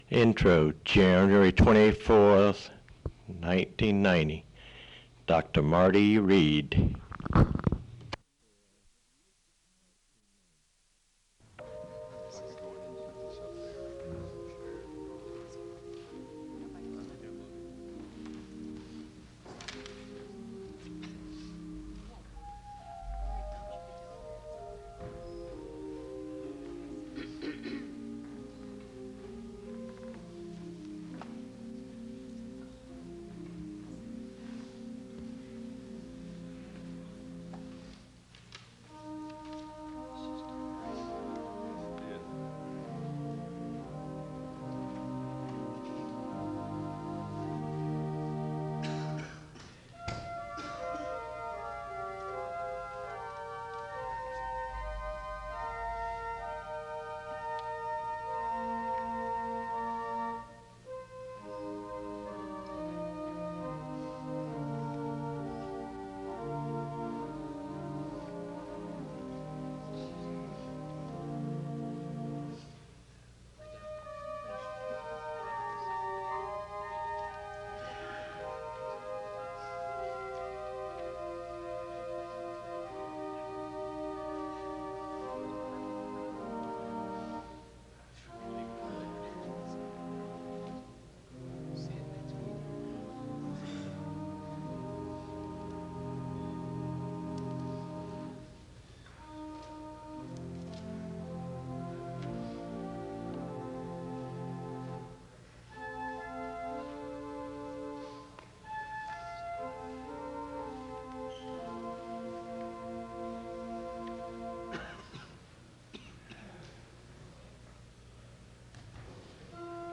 A hymn is played (0:09-4:57). A word of prayer is given (4:58-5:54).
A song of worship is sung (13:18-17:02).
A word of prayer ends the service (33:51-34:25).